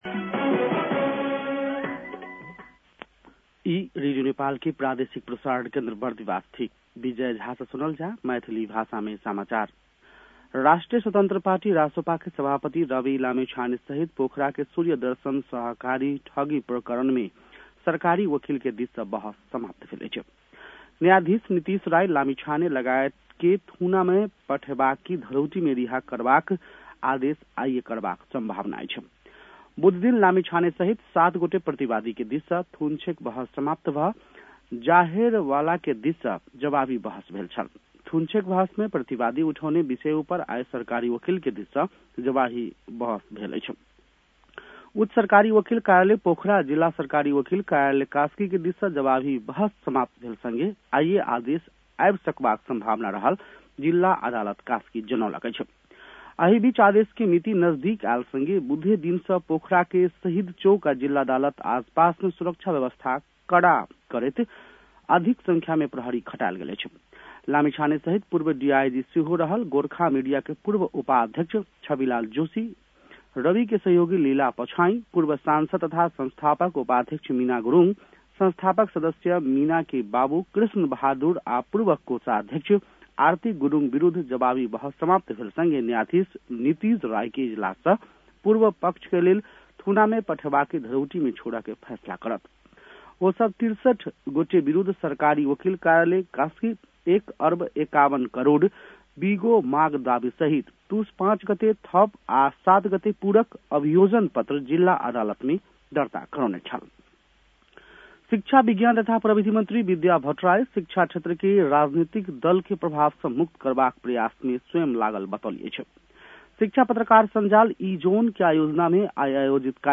मैथिली भाषामा समाचार : २६ पुष , २०८१
Maithali-news-9-25.mp3